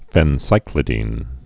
(fĕn-sīklĭ-dēn, -dĭn, -sĭklĭ-)